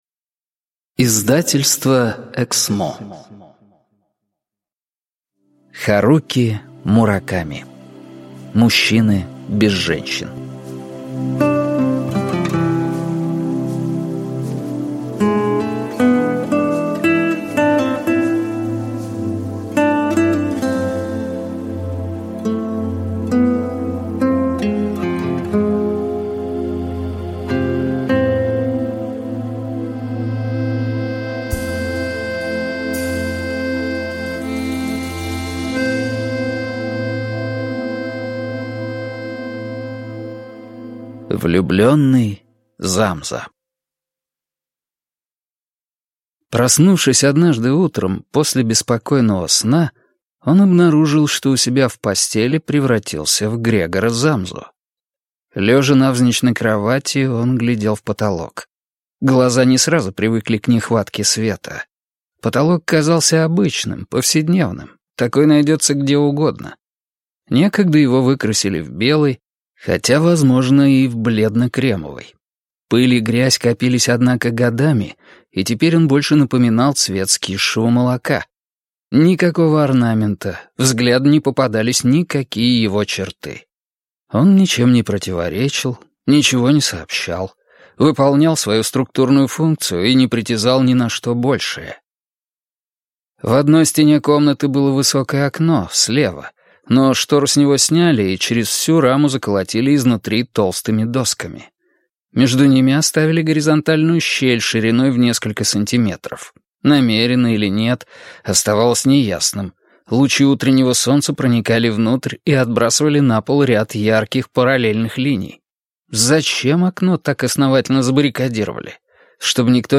Аудиокнига Мужчины без женщин | Библиотека аудиокниг